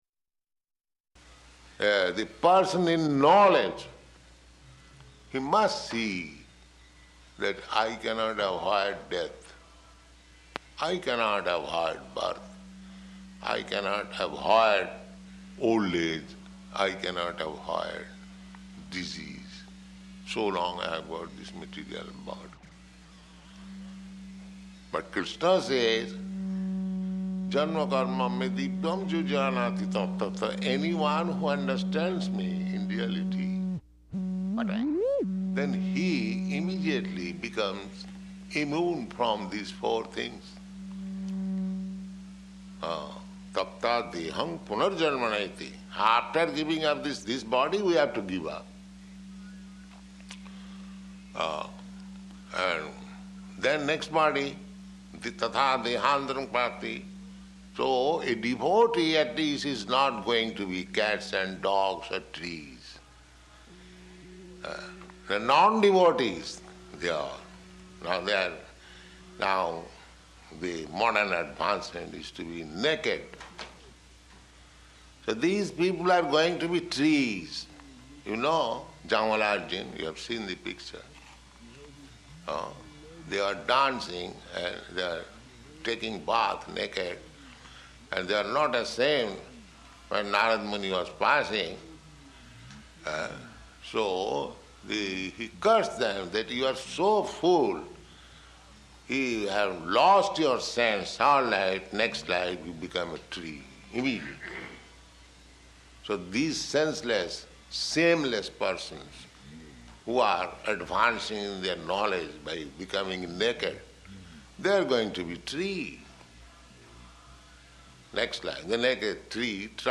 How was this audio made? Lecture [partially recorded] Location: Los Angeles